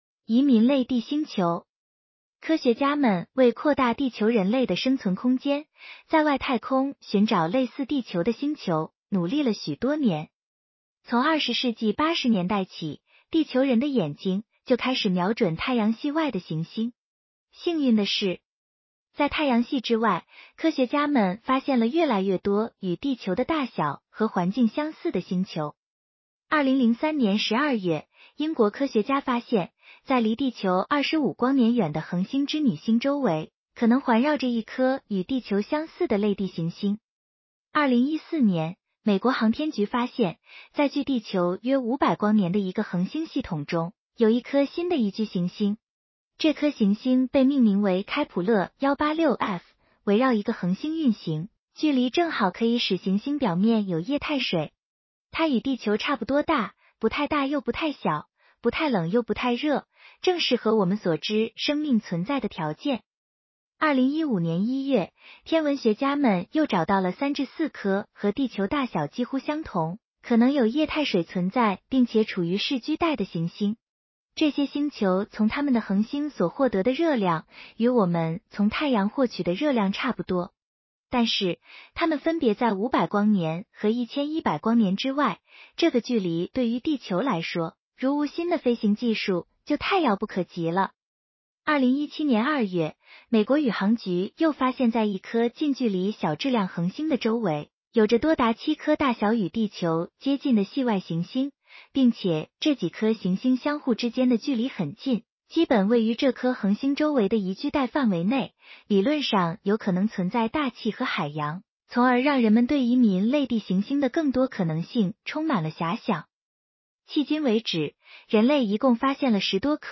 (下面是语音播讲片段)